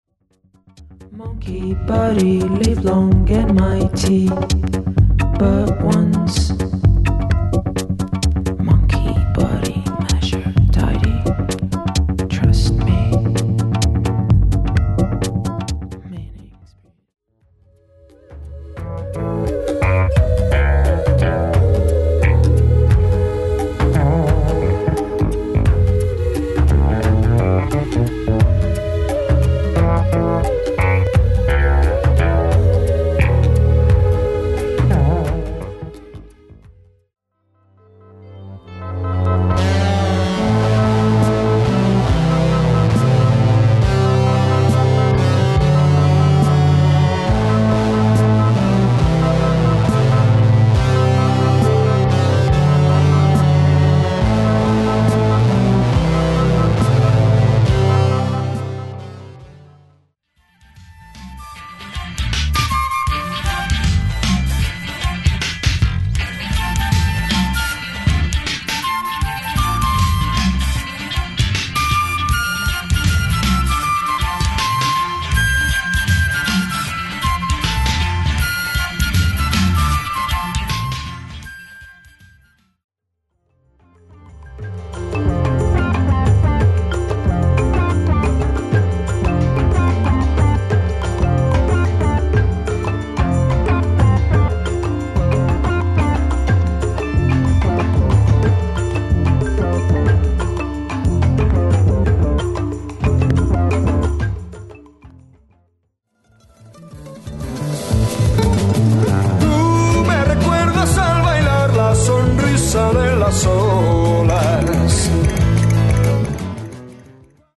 Dub～New Wave～Disco～House～Jazzとオールジャンルをスムーズに聴かせる
Dub New Wave Disco House Mix Tape